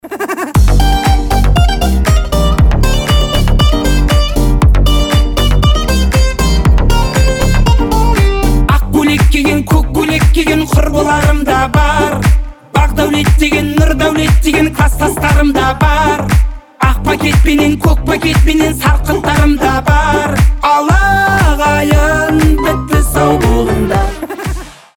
веселые гитара позитивные
свадебные